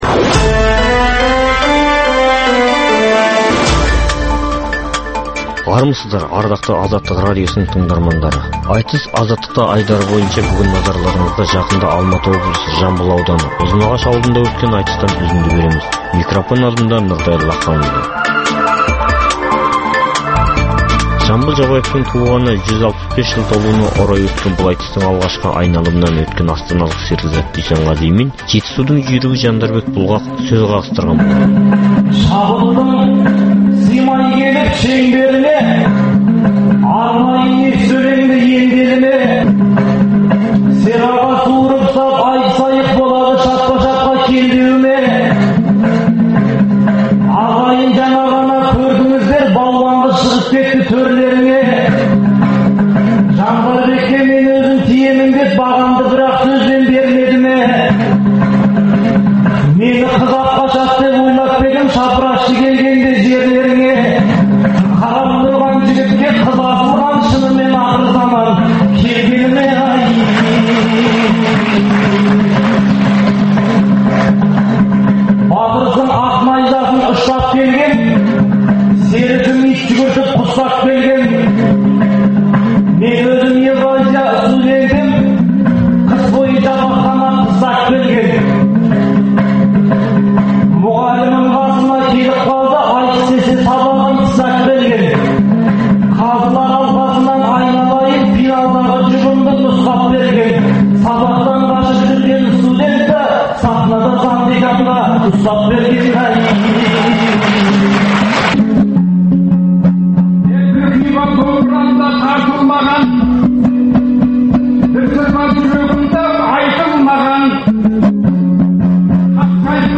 Алматы облысы Ұзынағаш ауылында өткен ақындар айтысының екінші айналымында